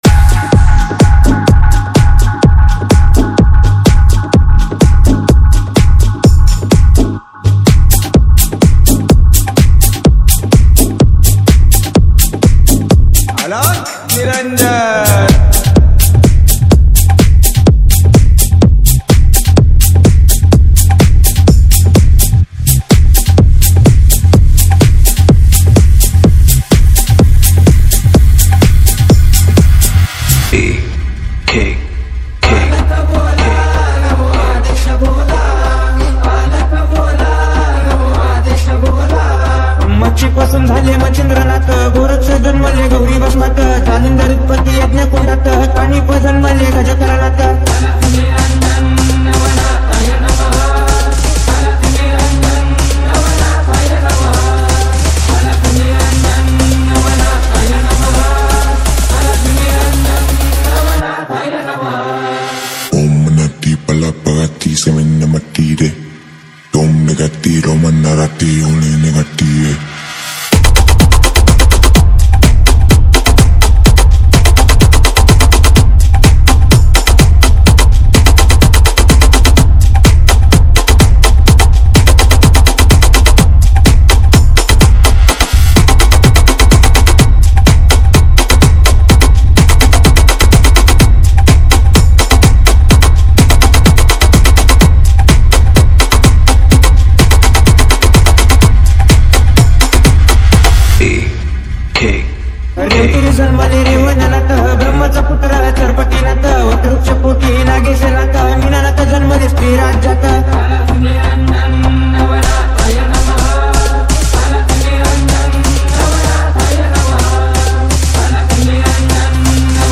• Category: MARATHI SOUND CHECK